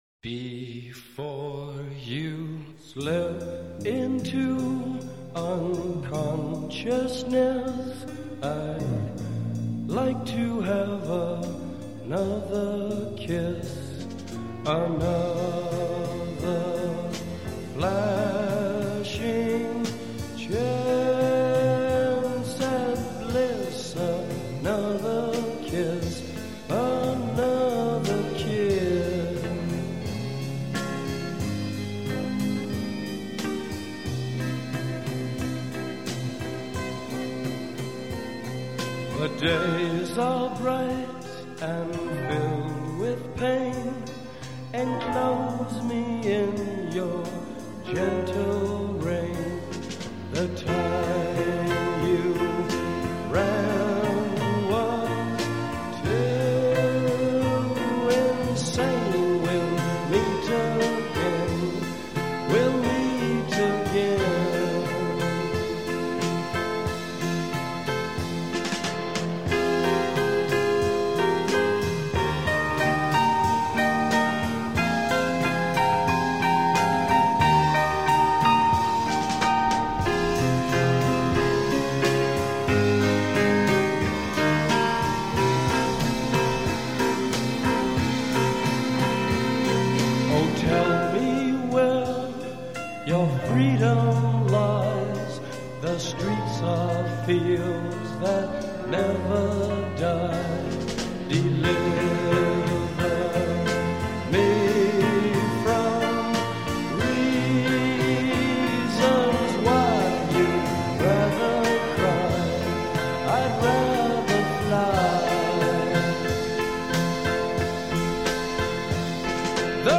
guitar
vocals
drums